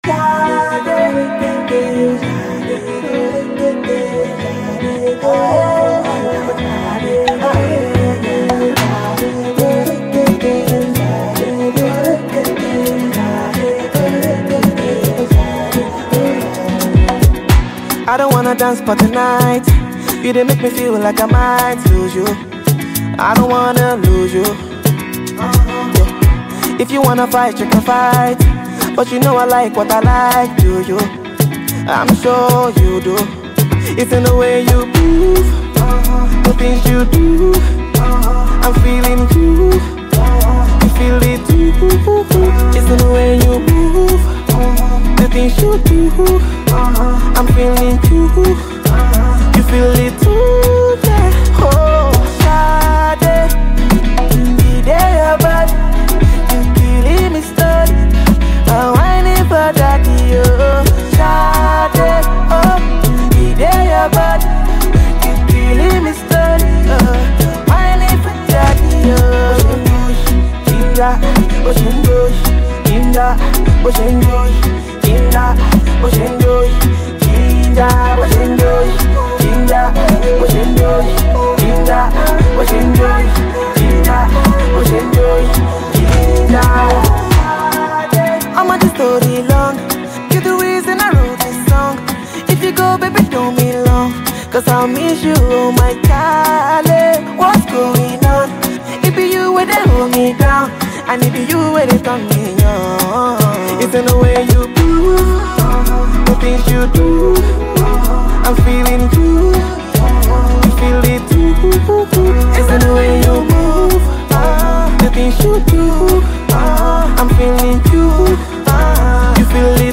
melodic percussion